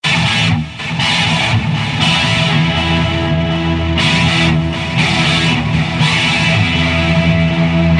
老式磁带延时
描述：我的12弦
标签： 120 bpm Rock Loops Guitar Electric Loops 1.35 MB wav Key : Unknown
声道立体声